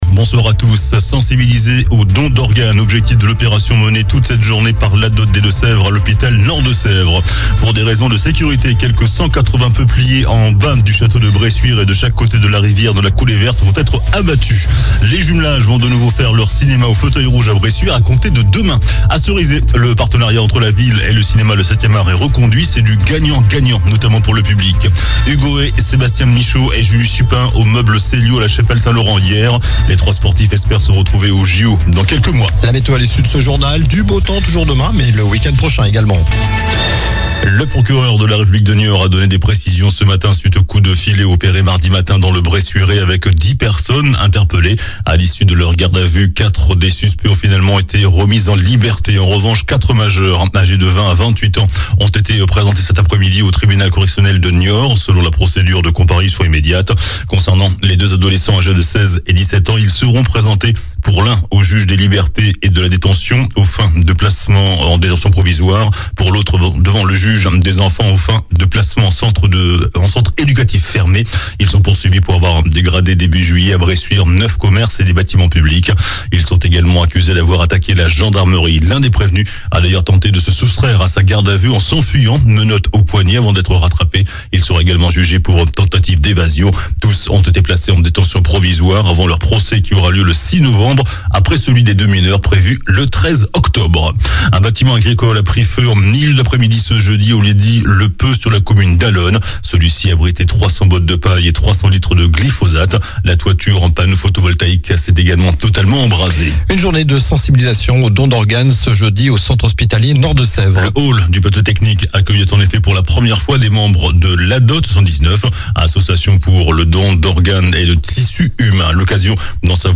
JOURNAL DU JEUDI 28 SEPTEMBRE ( SOIR )